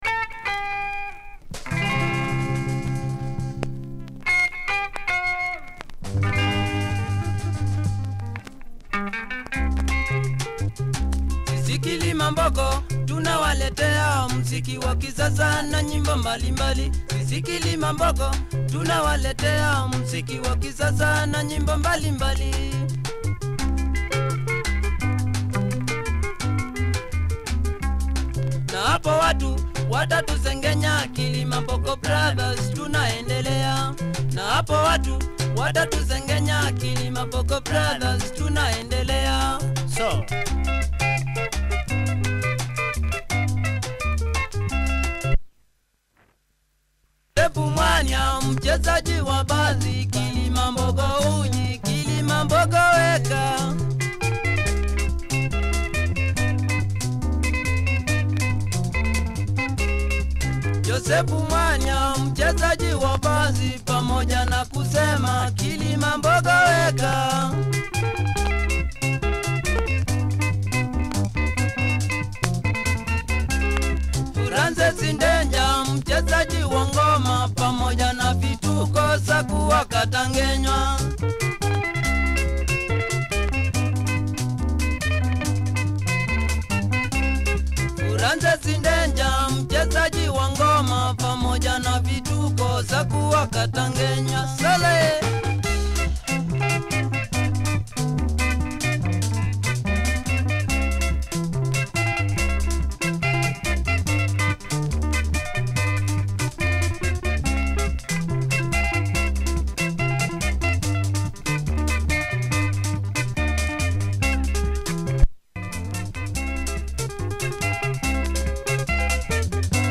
Quality Kamba benga mover